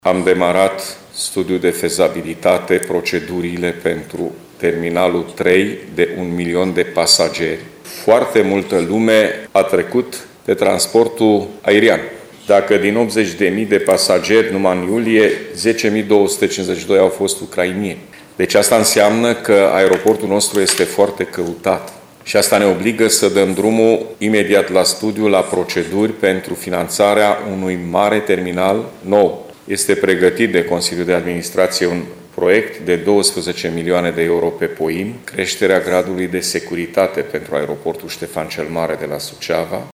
Președintele Consiliului Județean Suceava, GHEORGHE FLUTUR, a declarat că, după finalizarea celui de-al 2 –lea terminal de pasageri, este în proiect un al 3 –lea terminal.